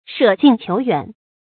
注音：ㄕㄜˇ ㄐㄧㄣˋ ㄑㄧㄡˊ ㄧㄨㄢˇ
舍近求遠的讀法